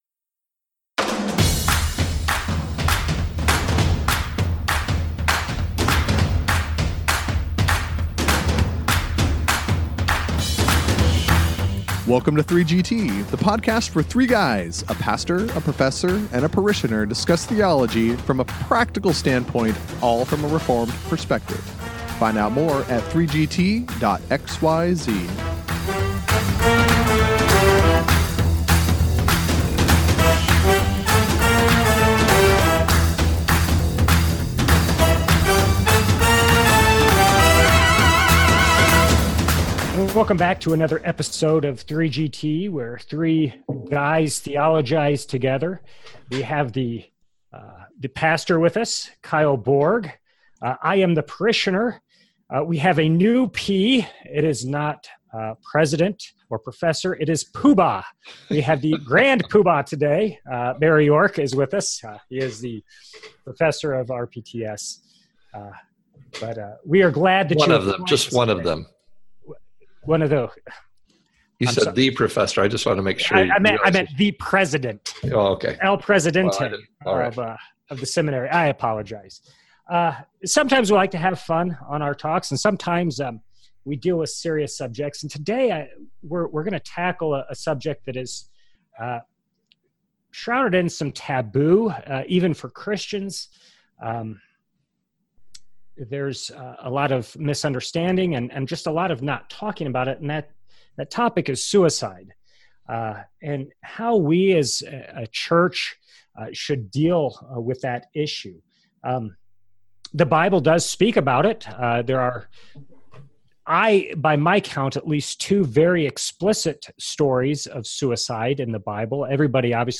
Having been affected by suicide in their families, churches, and communities, the men talk about this epidemic in our nation.
Join 3GT for this important discussion.